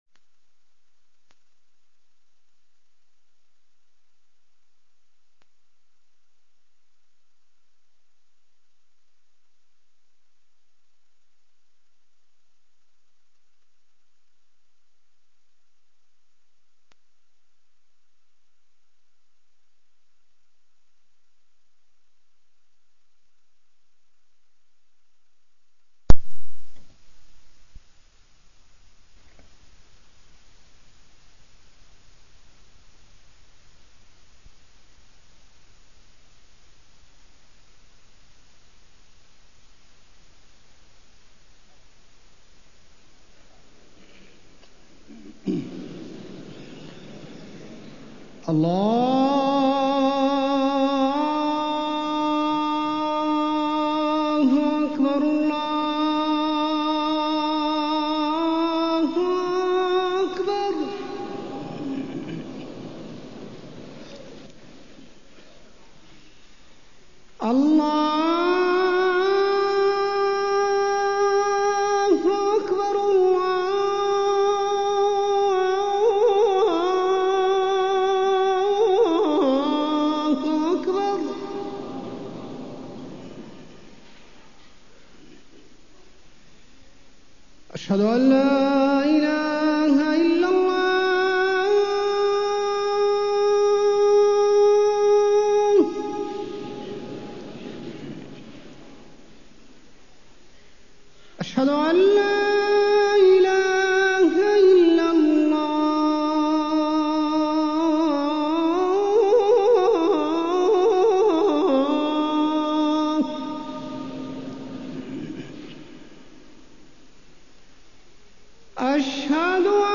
تاريخ النشر ٢٧ صفر ١٤٢٣ هـ المكان: المسجد النبوي الشيخ: فضيلة الشيخ د. حسين بن عبدالعزيز آل الشيخ فضيلة الشيخ د. حسين بن عبدالعزيز آل الشيخ واقع الأمة الإسلامية